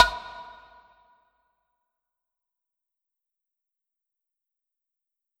Snare (Good Morning).wav